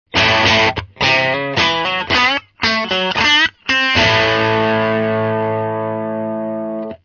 ・Guitar　（Fender Telecaster：広いレンジを持つピックアップが乗っていますので)
(NoEQ,NoEffectで掲載しています）
No1. MP3 ★★ Canareをアンプ直、というセッティングは僕にとっては良いリファレンスになります。
サウンドは派手さは全くありませんし、パワー感もそれ程ありません。
比較的おとなしいキャラクター、と言うべきでしょう。